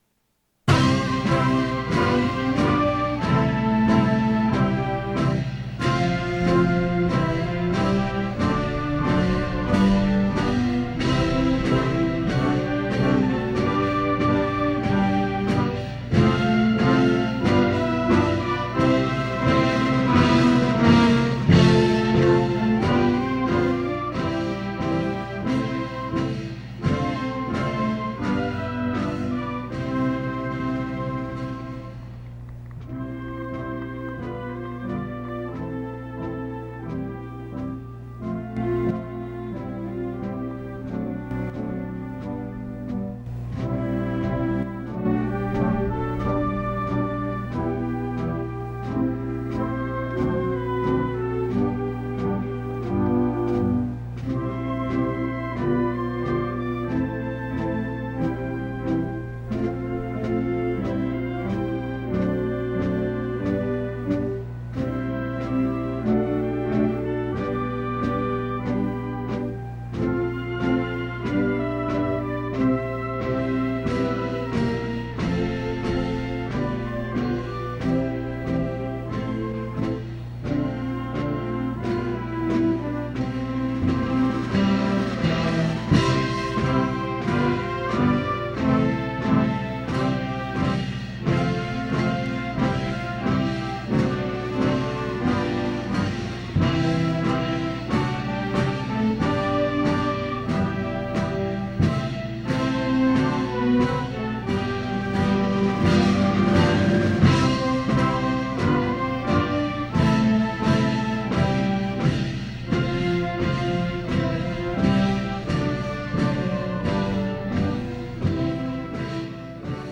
The June 1965 convocation takes place in Spartan Stadium, on the campus of Michigan State University (MSU).
Earl Warren, Chief Justice of the U.S. Supreme Court, gives the commencement address and is given an honorary Doctor of Laws degree. The conferring of honorary degrees continues, and Distinguished Alumni Awards are presented.
Original Format: Open reel audio tape